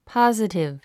発音
pɑ’zətiv　ポォジティブ